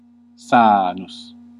Ääntäminen
US : IPA : [ˈsɛn.sə.bəl] RP : IPA : /ˈsensəbl/